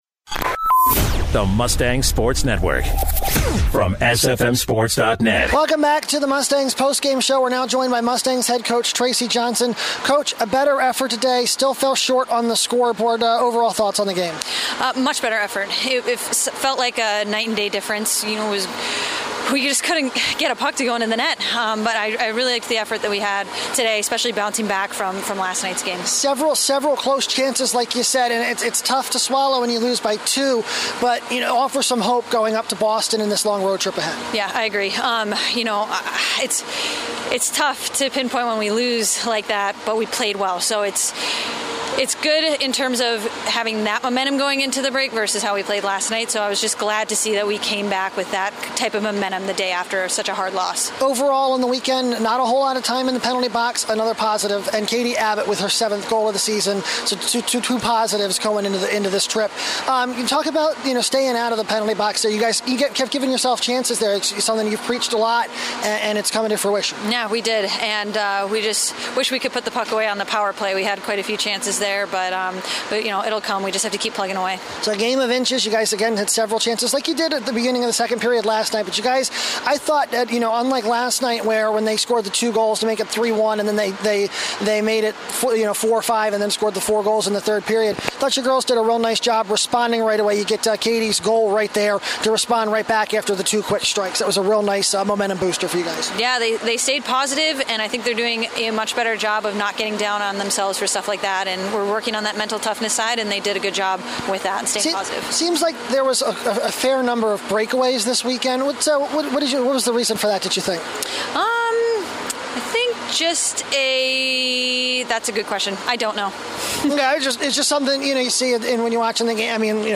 Despite a strong effort, the Mustangs drop the weekend series to Hamline with a 4-2 Sunday loss. After the game